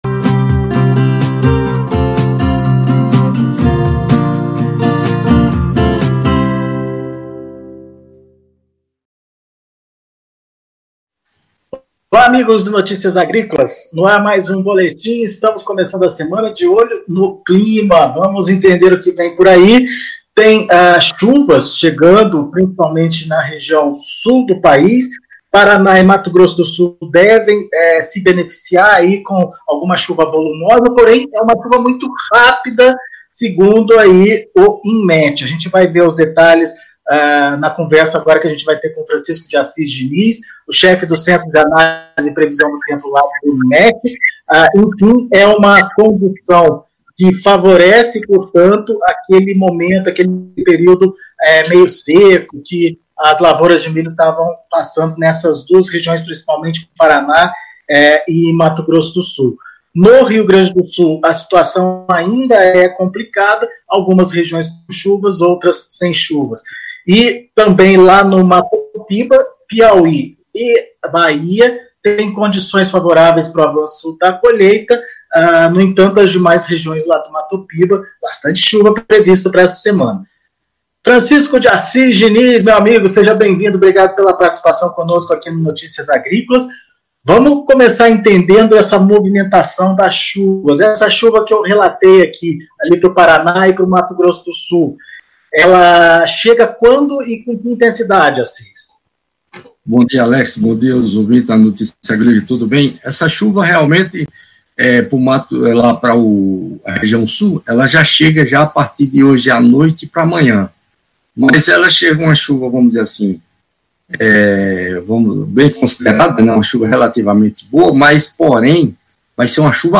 Previsão do Tempo - Entrevista